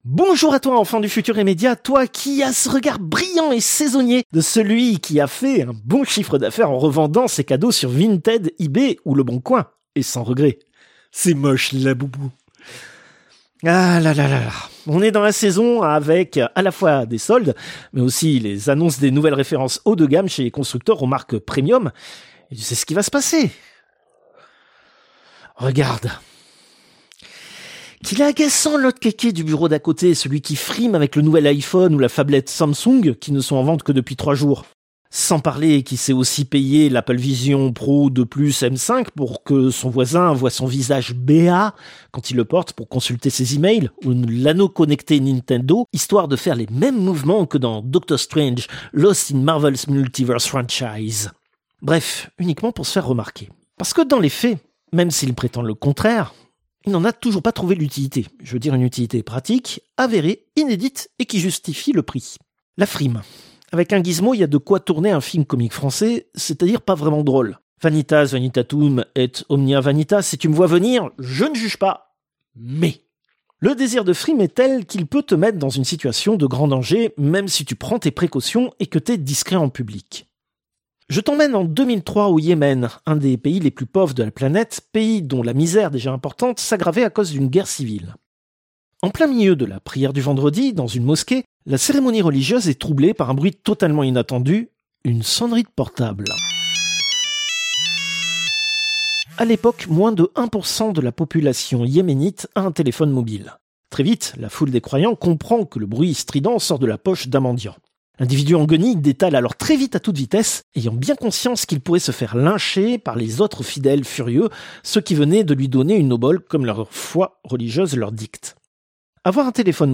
Extrait de l'émission CPU release Ex0228 : lost + found (janvier 2026).